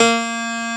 AcousticPiano(5)_A#3_22k.wav